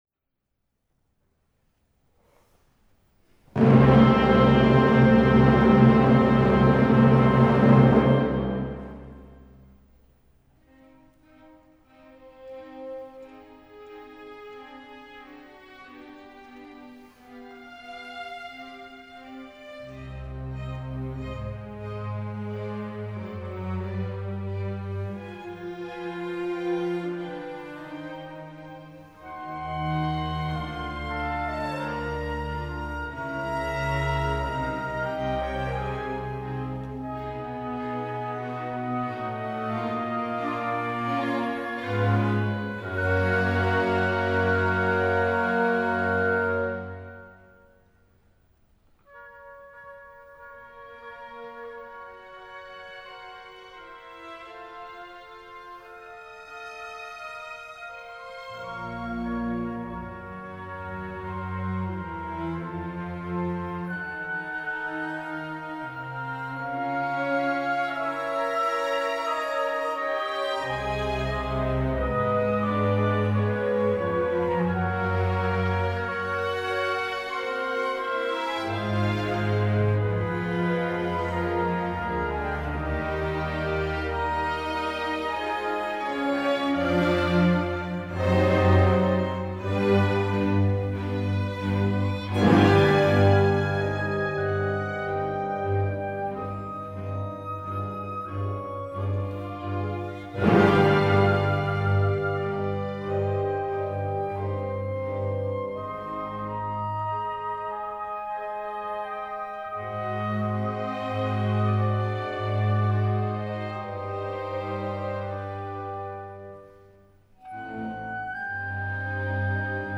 Categoría: Concert season